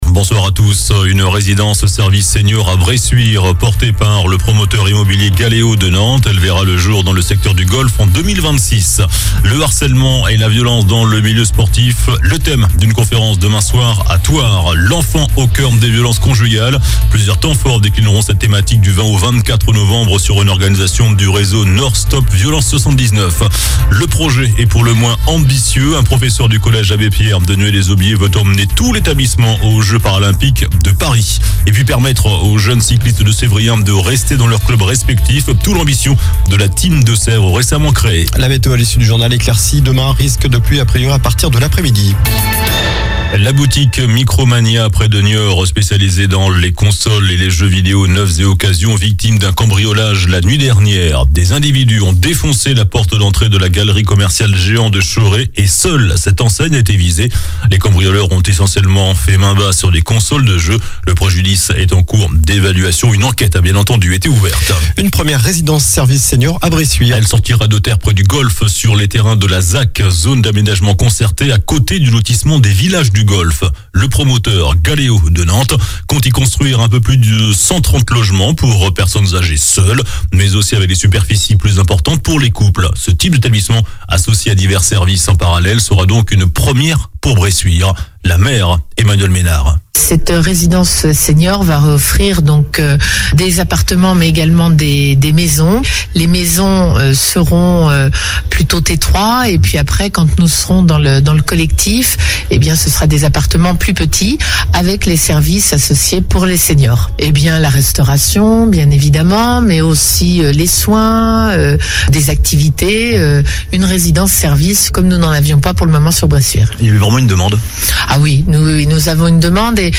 Journal du mercredi 08 novembre (soir)